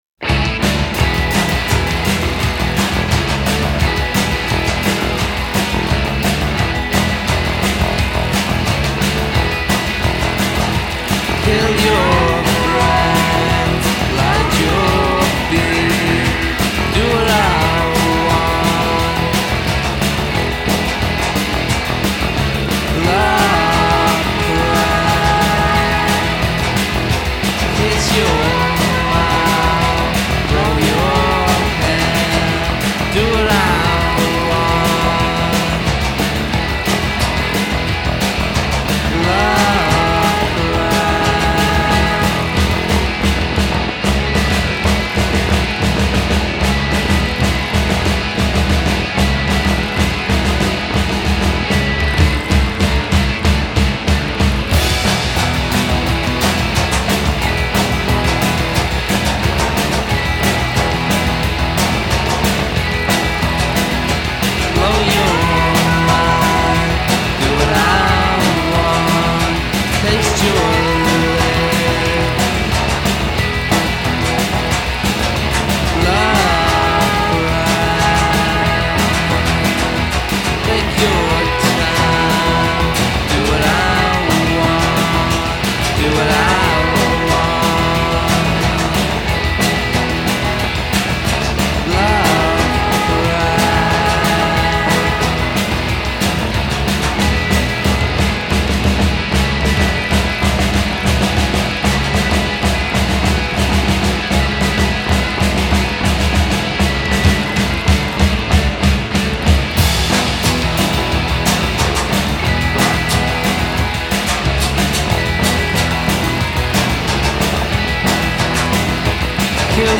Genre: Indie